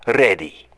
Modified Lasher Tank voice lines